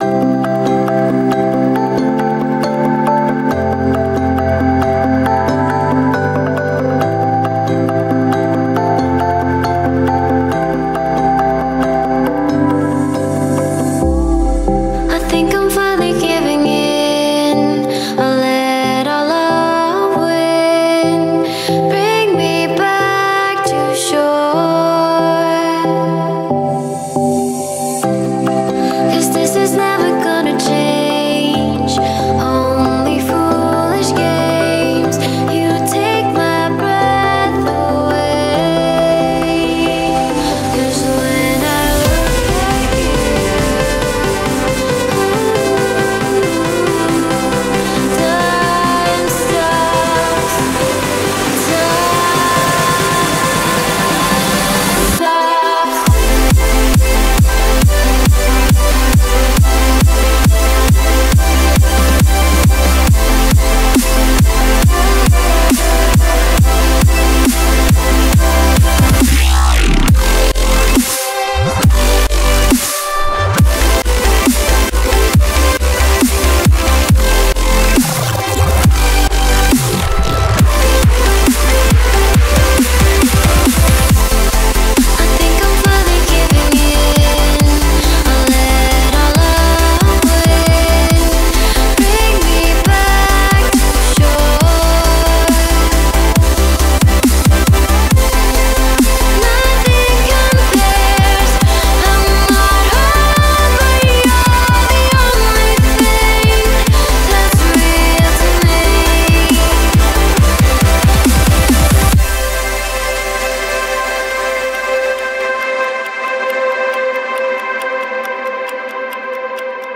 BPM137
MP3 QualityMusic Cut
tick, tock intensifies